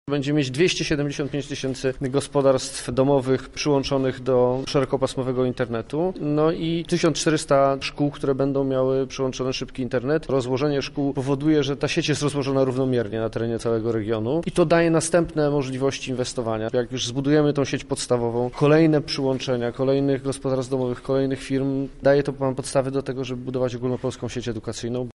– Mówimy o możliwościach rozwojowych – mówi Marek Zagórski, Minister Cyfryzacji: